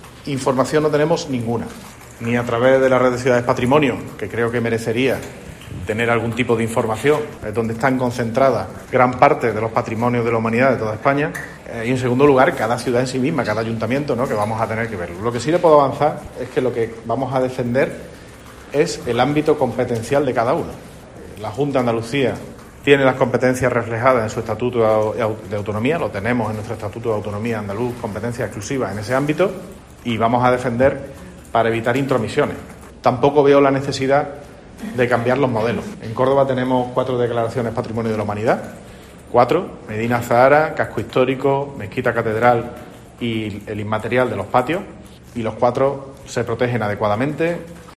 Así lo ha remarcado el regidor en declaraciones a los periodistas, a quienes ha comentado que en la reciente asamblea del Grupo de Ciudades Patrimonio, "de manera informal", le ha transmitido a la Comisión Ejecutiva del Grupo y al presidente "la preocupación por cómo va a quedar redactada definitivamente la ley".